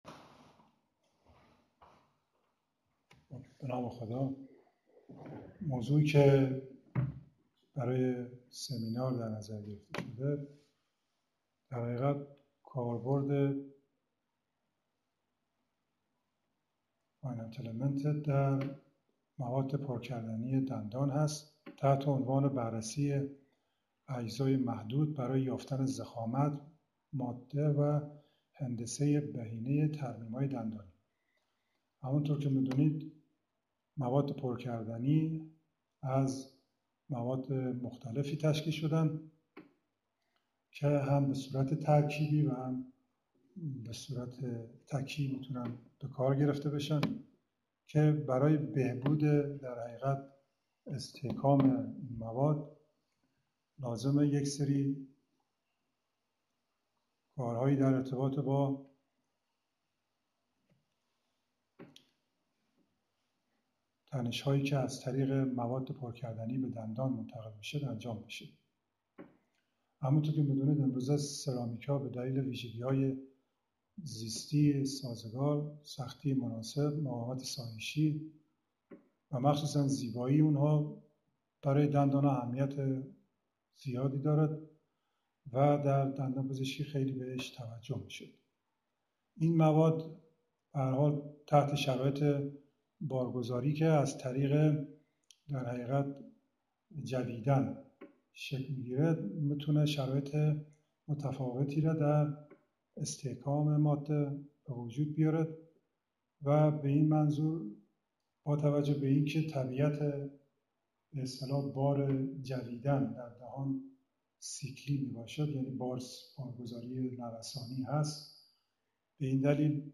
سخنرانی علمی: استفاده از روش اجزاء محدود براي يافتن ضخامت، ماده و هندسه ي بهينه ي ترميمهاي دنداني
فایل صوتی سخنرانی: